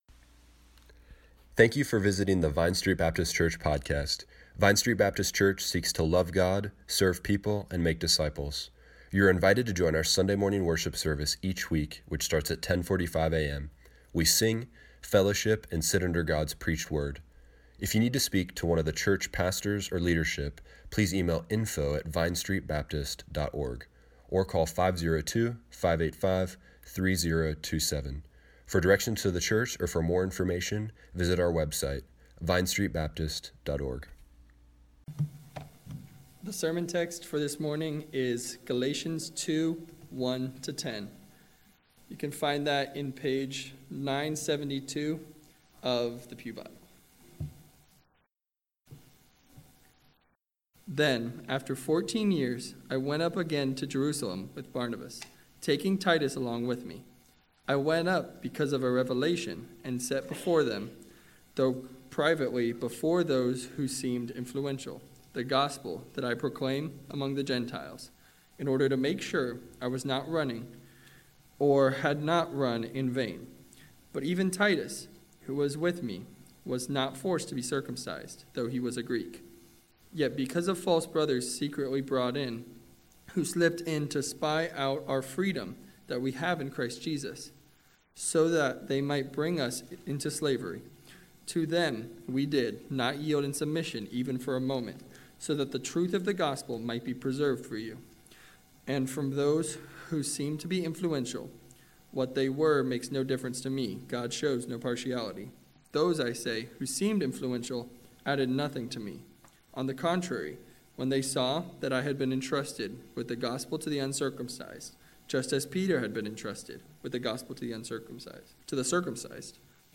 Our ministries are defined by our faithfulness to the gospel. Click here to listen to the sermon online.